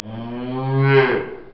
bull1.wav